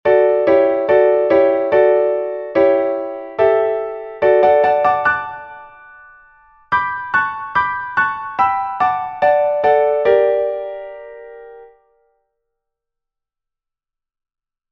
もちろん、１番のメロディはいつも同じなのですが、その下の２番と３番のパートは、微妙に変わっていて、そのためにハーモニーが変わっているのですよ。
一応、違いが分かるように、とてもゆっくりとしたテンポでそれぞれの音源を作ってみたので、聴き比べてみてください。